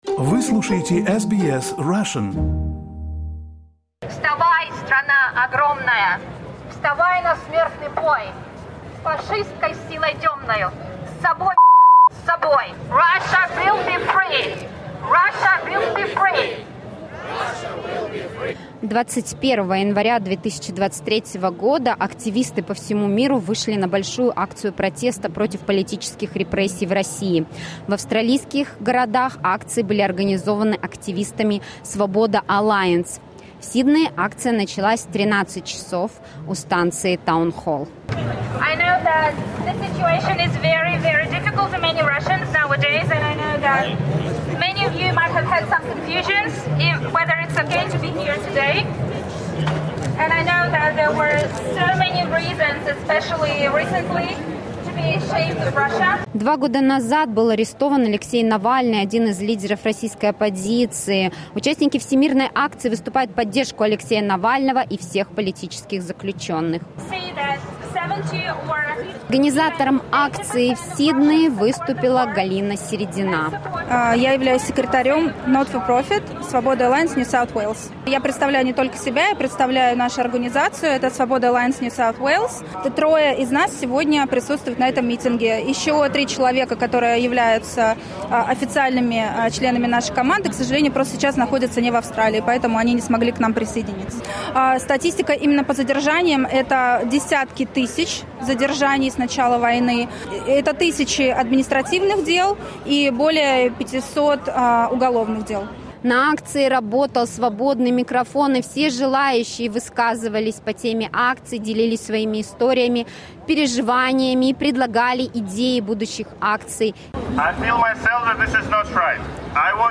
Reportage from the Sydney protest against political repression in Russia